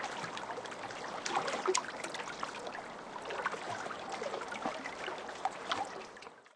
mcl_ambience - Ambient sounds for mcl2.
lake_waves_2_variety.ogg